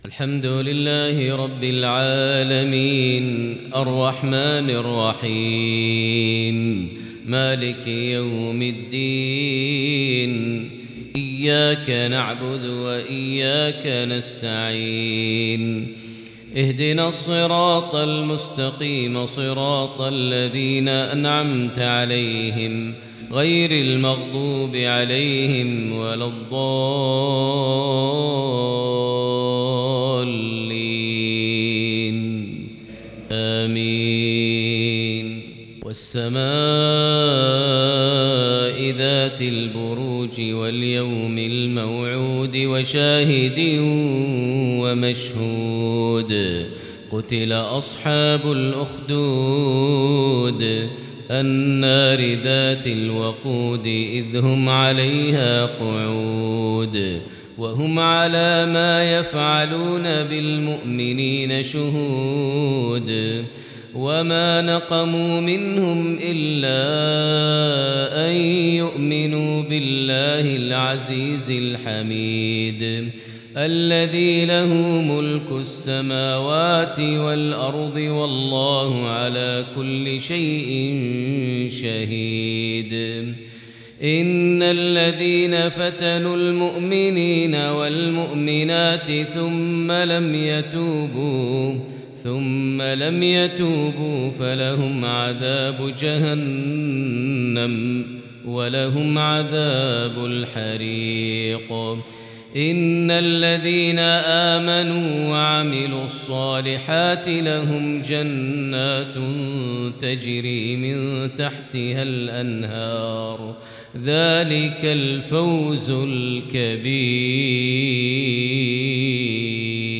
صلاة العصر الشيخان ماهر المعيقلي وأحمد بن طالب بن حميد